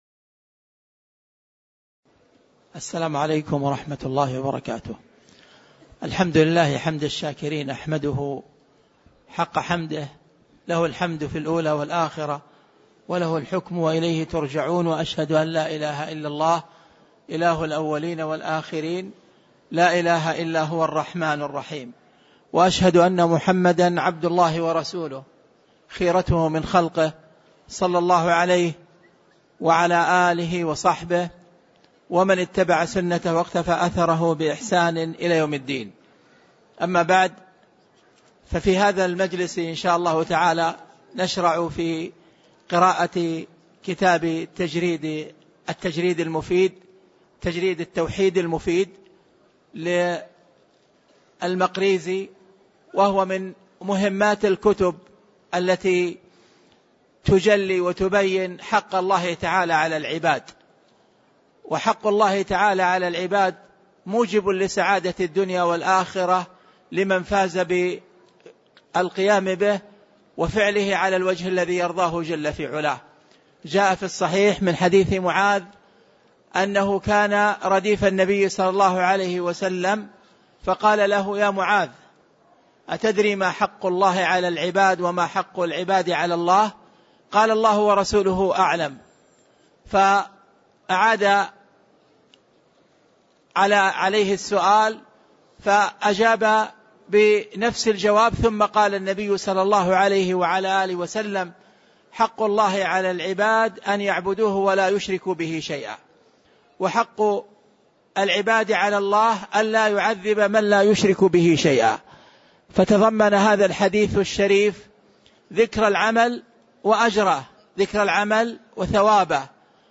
تاريخ النشر ٢٣ ربيع الثاني ١٤٣٩ هـ المكان: المسجد النبوي الشيخ